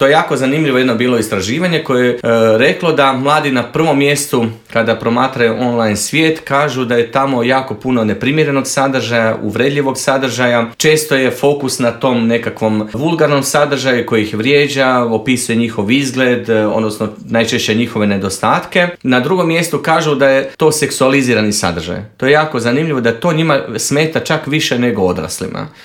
O svemu tome u Intervjuu Media servisa